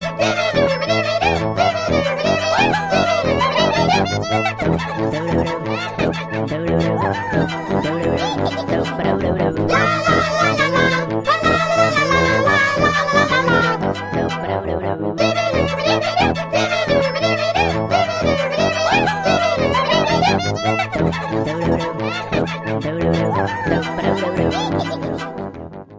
Catégorie Alarme/Reveil